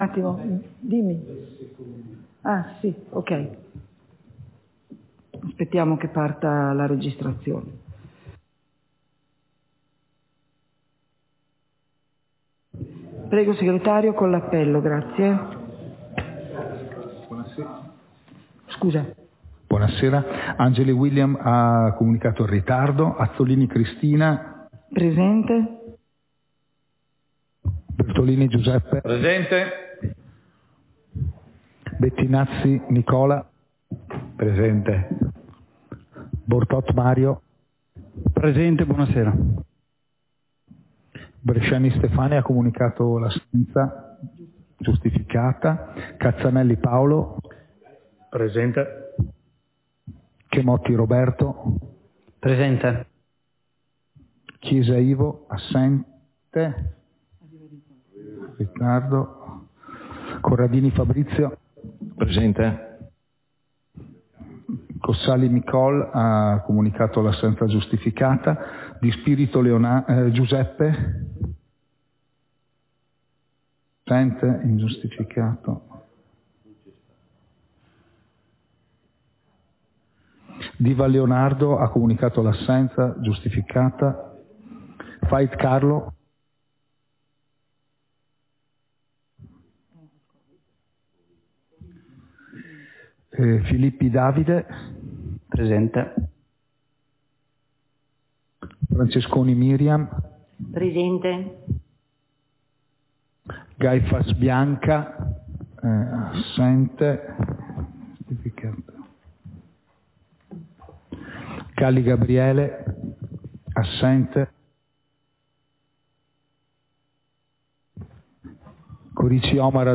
Seduta del consiglio comunale - 10.10.2023